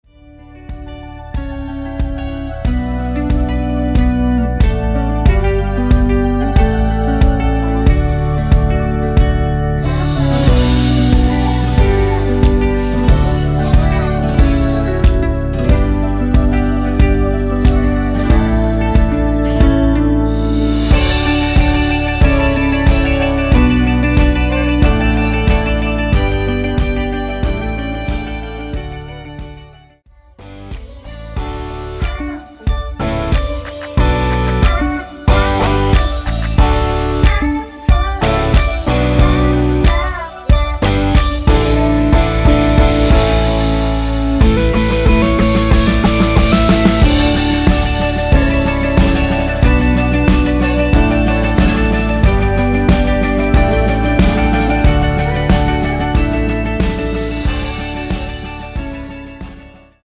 원키에서(+5)올린 멜로디와 코러스 포함된 MR입니다.(미리듣기 확인)
앞부분30초, 뒷부분30초씩 편집해서 올려 드리고 있습니다.